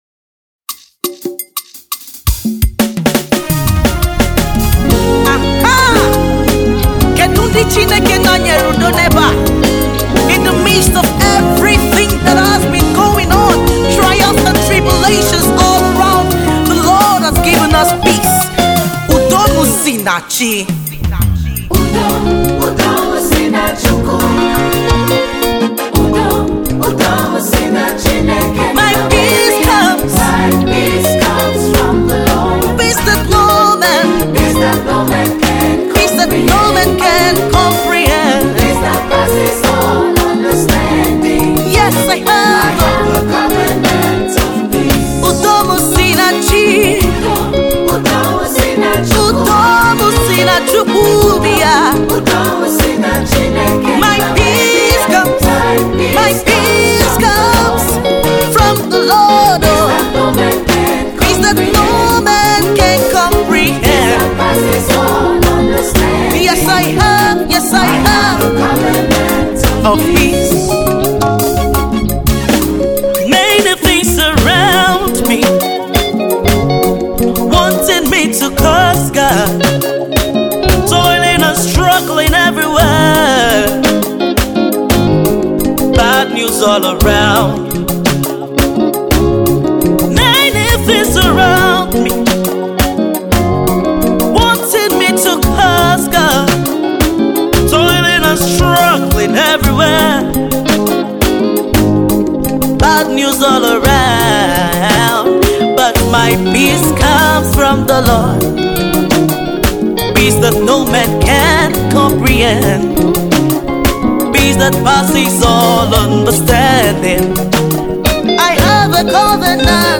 Nigerian gospel singer